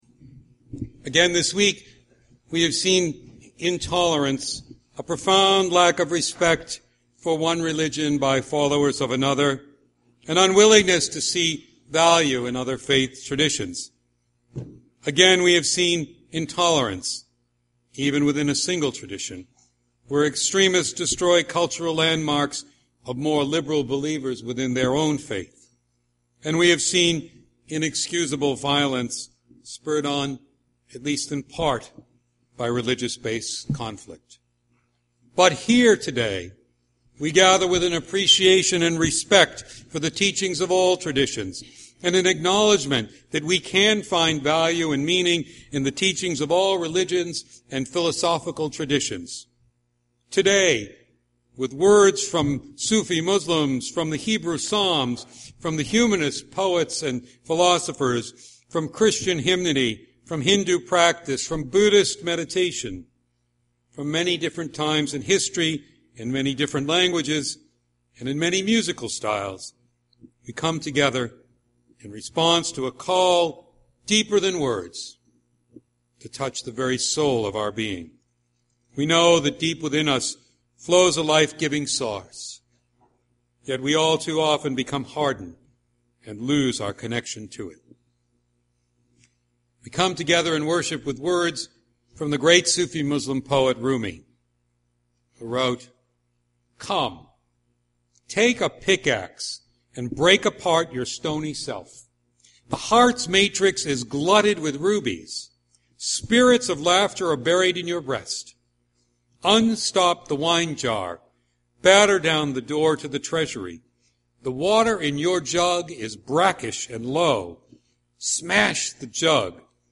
This Sunday we return to worship in our beautifully restored church and to two services.
In our worship we will focus on the importance of having a spiritual home and how this church is, or can be, that home for you. Our professional and volunteer musicians will be back in force with members of our jazz band playing at the 9 AM and 11 AM services.